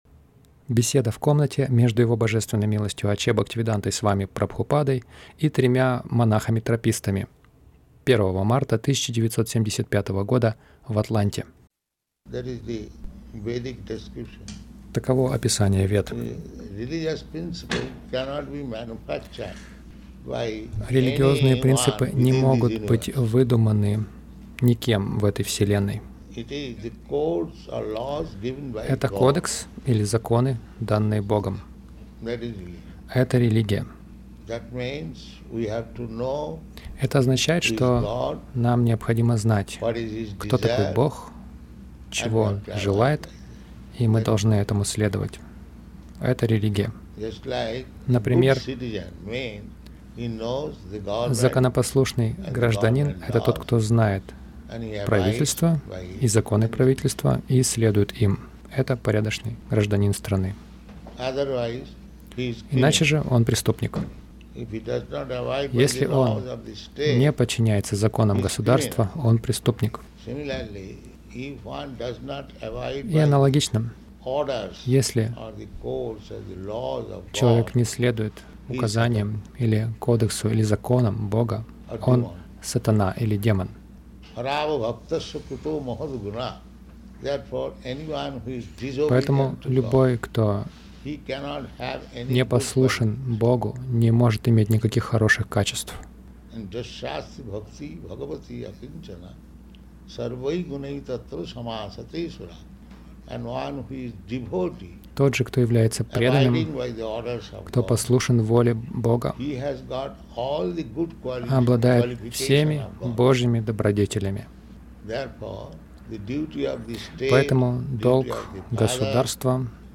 Беседа — С монахами о святом имени
Милость Прабхупады Аудиолекции и книги 01.03.1975 Беседы | Атланта Беседа — С монахами о святом имени Загрузка...